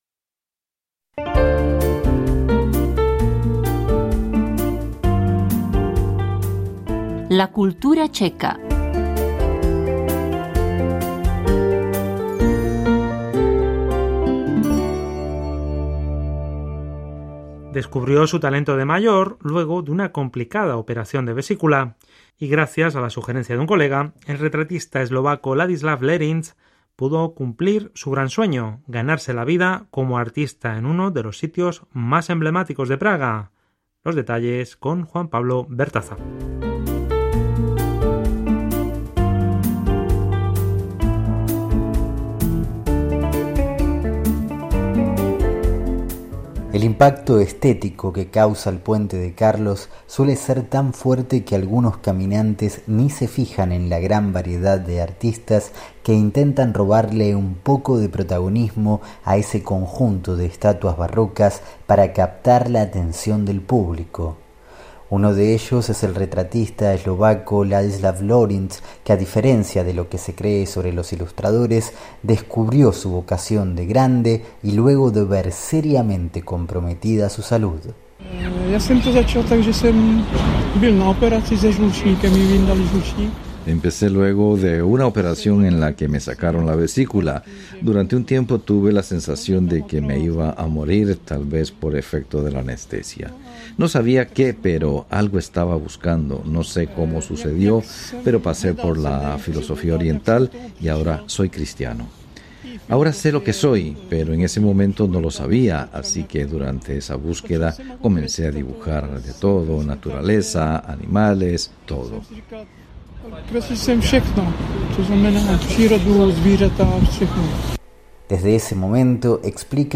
gracias al particular sonido que le saca al Handpan.